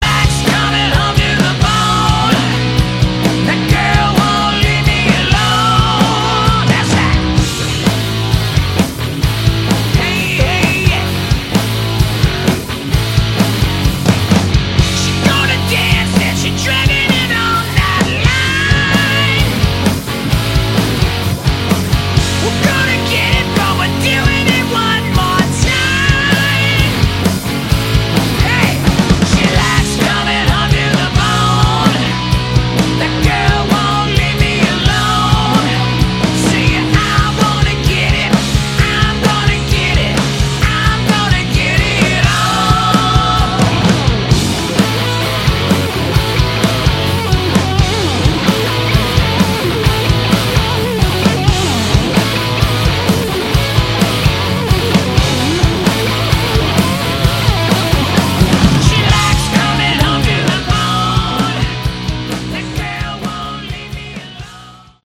Category: Hard Rock
vocals, guitar
guitar
bass
drums